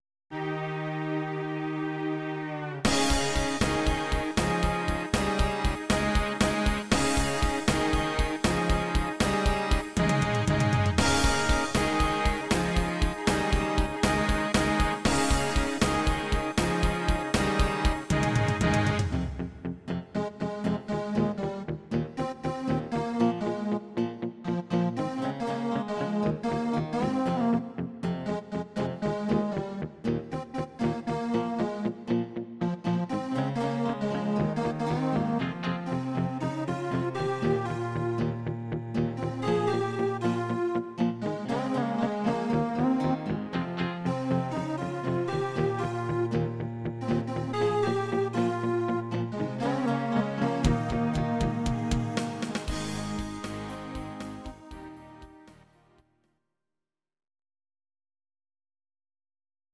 Audio Recordings based on Midi-files
Pop, Rock, Musical/Film/TV, 2000s